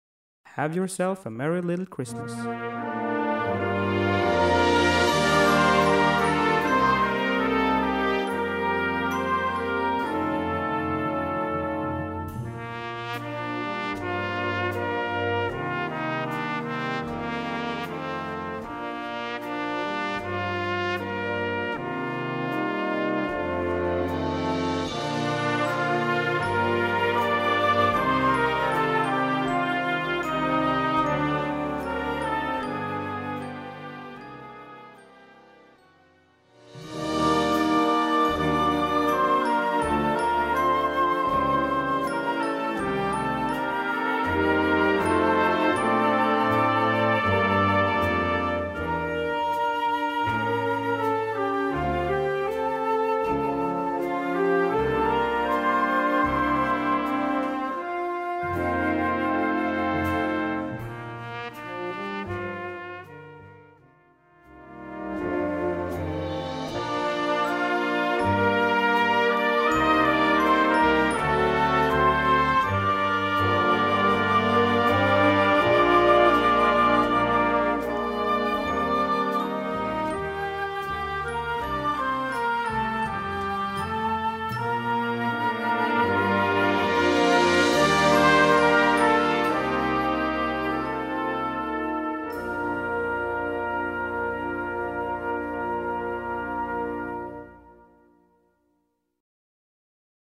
Gattung: Weihnachtsmusik
Besetzung: Blasorchester
Die Melodie wandert durch alle Register des Ensembles.